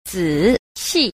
2. 仔細 – zǐxì – tử tế (tỉ mỉ, kỹ lưỡng, cẩn thận)
zi_xi.mp3